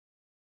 sounds / monsters / rat / idle_3.ogg